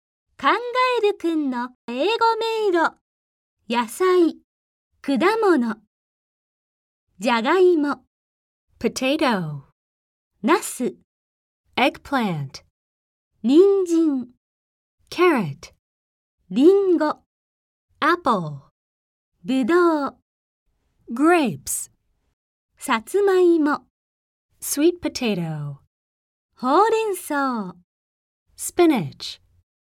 ネイティブスピーカーによる発音でお聞きいただけます。